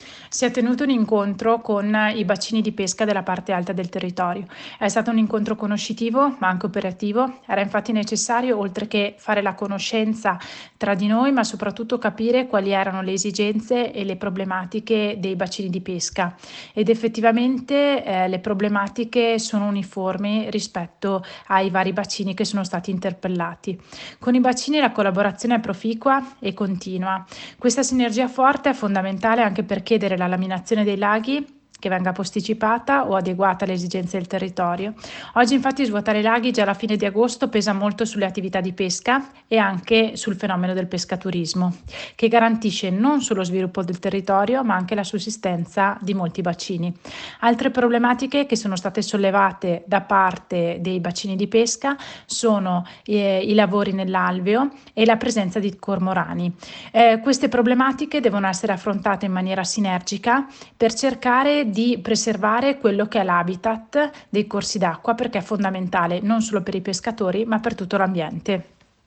ai microfoni di Radio Più la vicepresidente della Provincia Silvia Calligaro